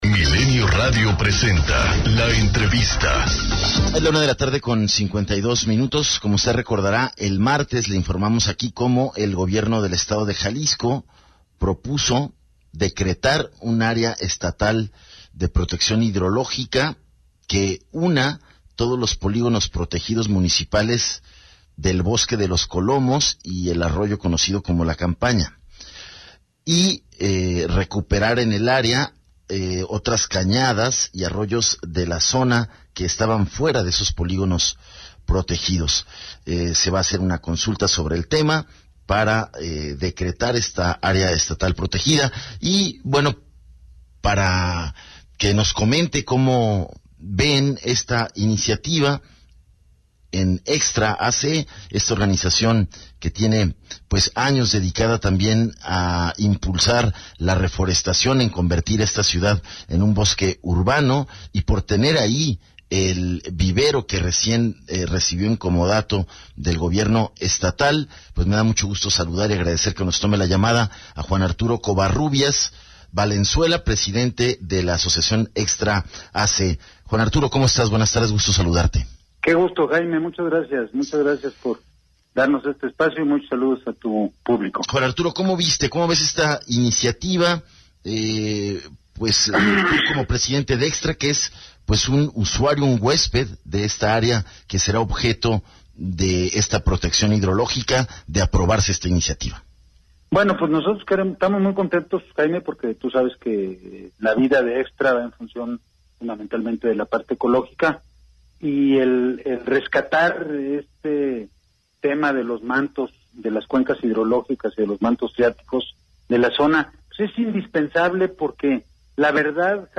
ENTREVISTA 280815